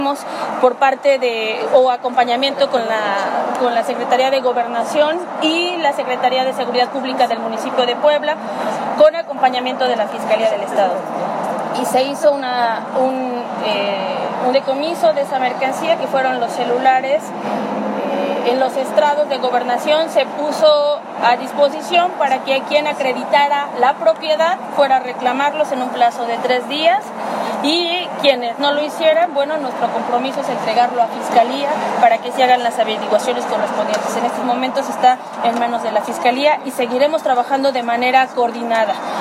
En entrevista posterior a la entrega de trabajos que se efectuaron de mantenimiento de La Recta a Cholula, Rivera Vivanco precisó que los celulares fueron decomisados mediante la aplicación de un operativo que se efectuó el pasado 20 de marzo entre el Grupo de Coordinación Territorial para la Construcción de la Paz, y en coordinación con la Secretaría de Gobernación Municipal, donde se aseguraron 231 equipos de telefonía móvil de dudosa procedencia que eran comercializados en el Centro Histórico por ambulantes.